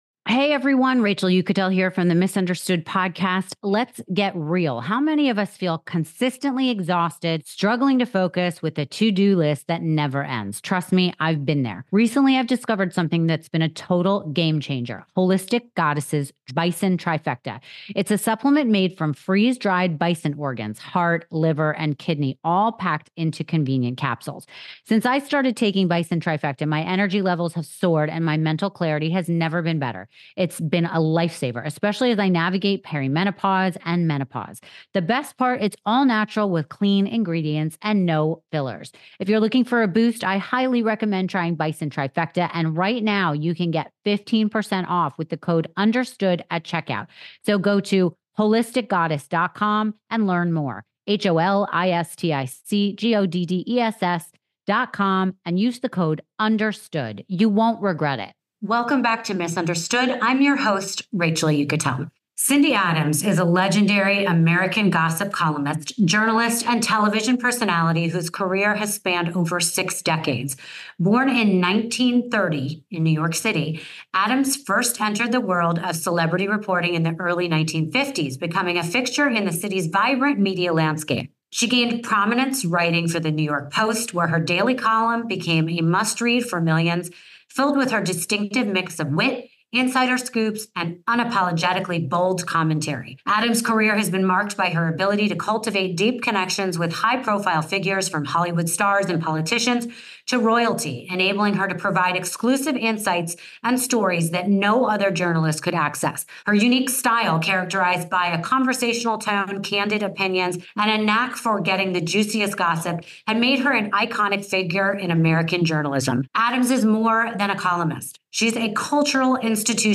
Cindy Adams joins us in New York City for a rare sit-down, bringing decades of insider stories from Hollywood, politics, and high society.